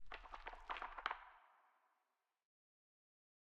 Minecraft Version Minecraft Version 1.21.5 Latest Release | Latest Snapshot 1.21.5 / assets / minecraft / sounds / block / pale_hanging_moss / pale_hanging_moss13.ogg Compare With Compare With Latest Release | Latest Snapshot
pale_hanging_moss13.ogg